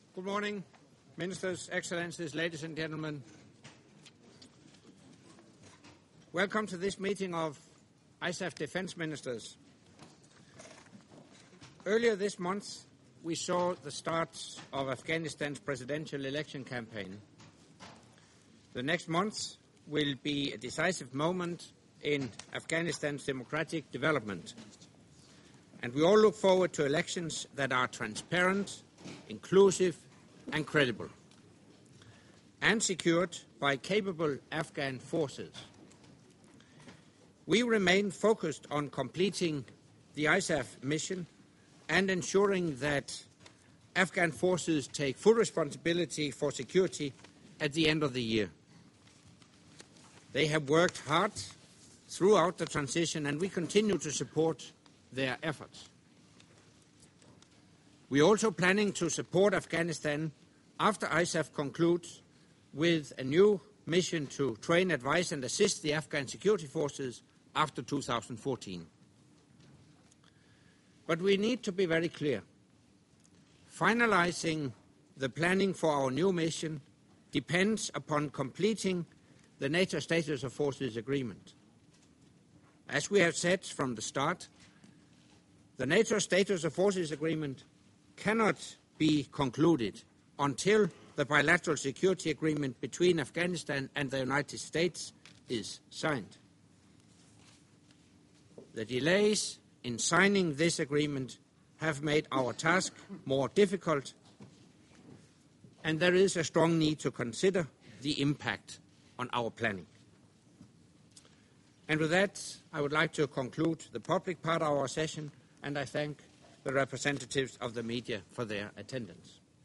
FRENCH - Opening remarks by NATO Secretary General Anders Fogh Rasmussen at the meeting of the NATO Defence Ministers with non-NATO ISAF contributing nations